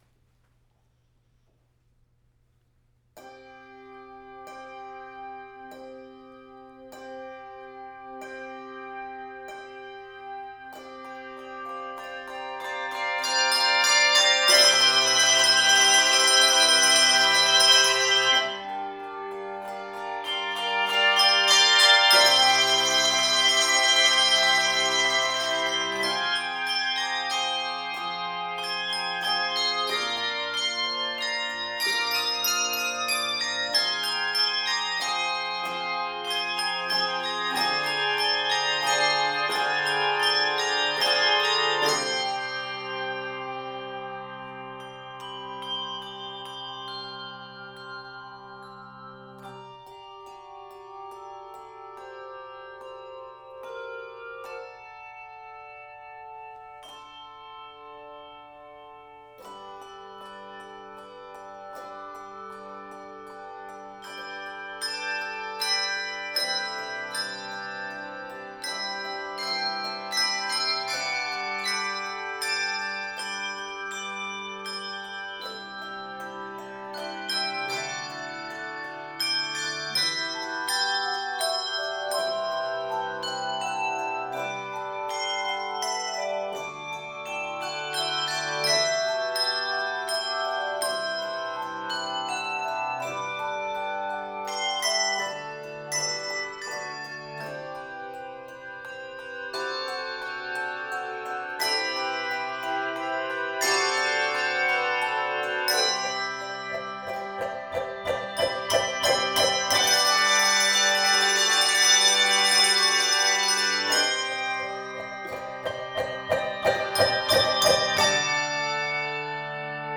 Voicing: HC 3-6